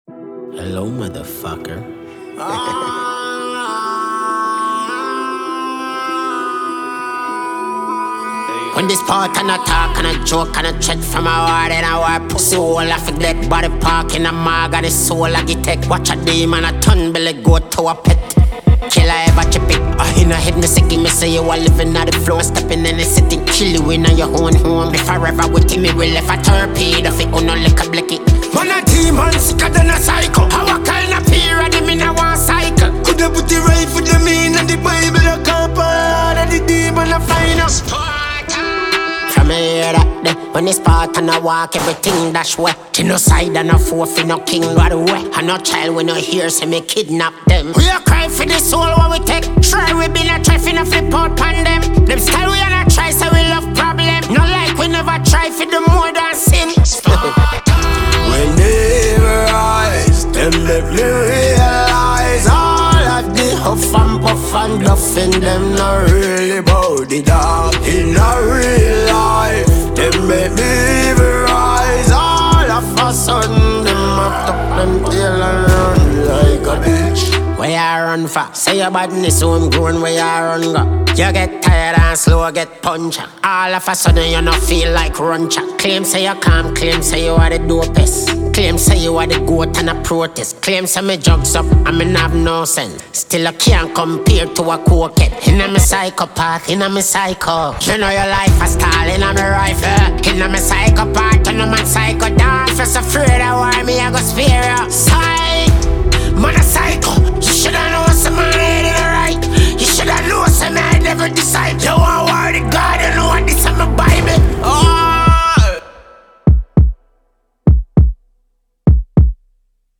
Dancehall
• Genre: Dancehall